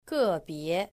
• gèbié